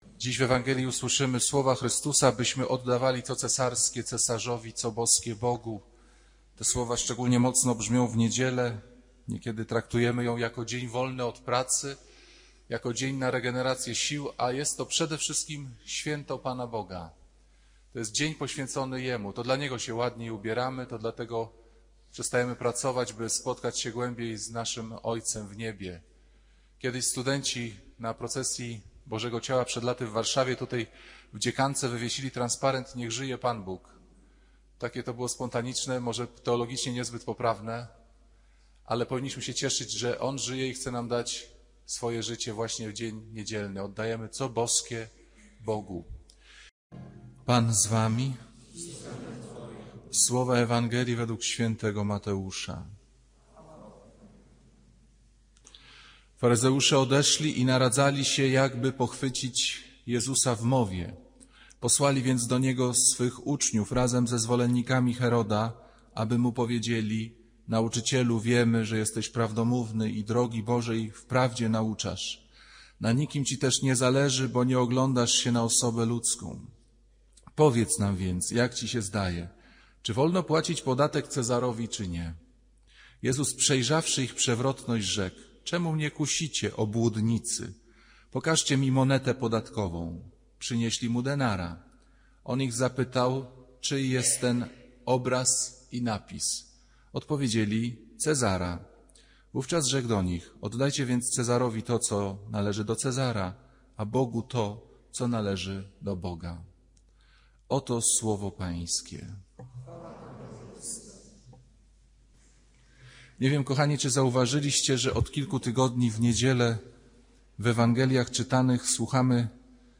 To kazanie to jedno z najmocniejszych duchowo ostrzeżeń przed największym zagrożeniem wiary: odejściem od Boga przez religijność. Ksiądz Piotr Pawlukiewicz z wielką stanowczością i czułością pokazuje, że można modlić się, chodzić do Kościoła, być aktywnym – i jednocześnie żyć z dala od Boga.